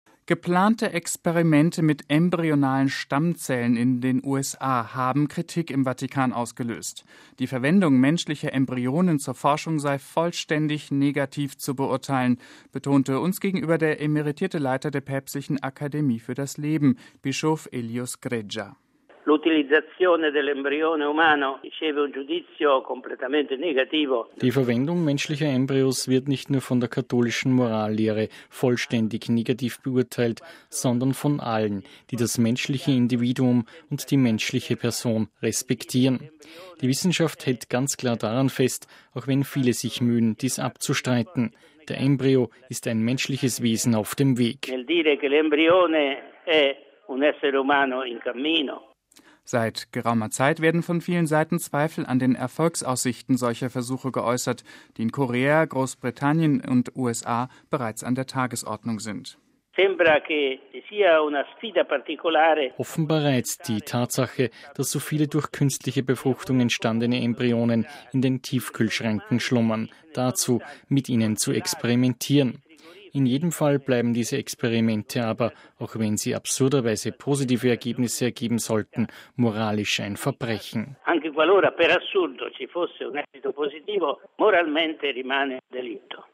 Die Verwendung menschlicher Embryonen zur Forschung sei vollständig negativ zu beurteilen, betonte uns gegenüber der emeritierte Leiter der Päpstlichen Akademie für das Leben, Bischof Elio Sgreccia.